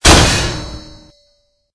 CHQ_FACT_stomper_med.ogg